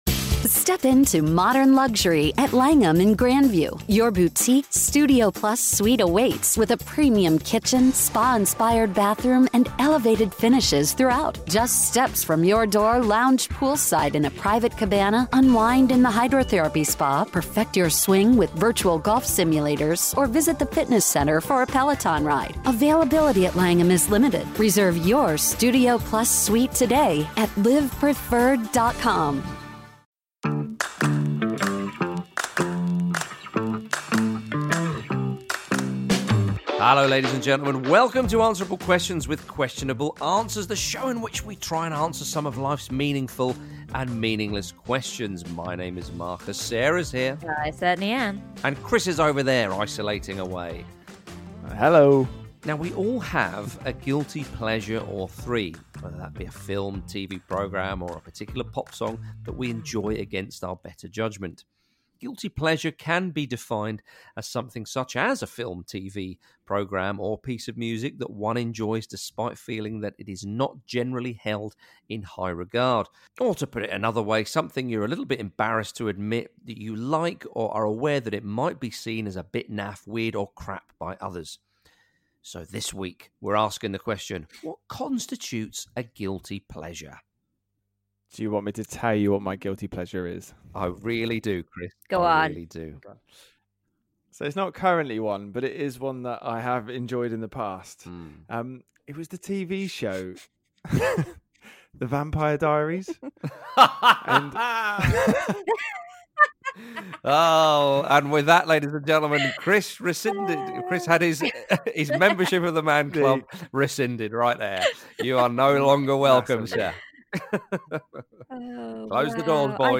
This week the AQQA team talk about what actually constitutes a guilty pleasure and throw in some of their own